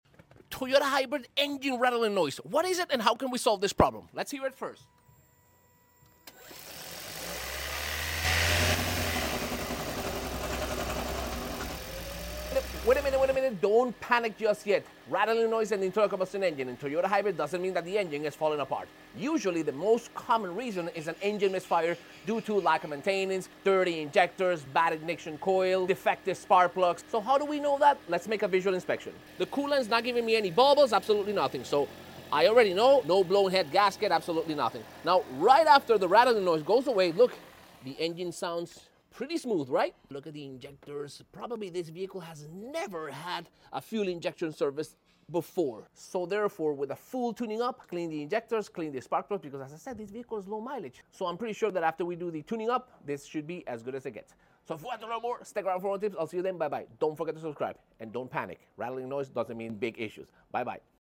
That Rattling Noise in Your Toyota Hybrid is Fixable 🚗✨" Hearing a rattling noise in your Toyota hybrid during a cold start?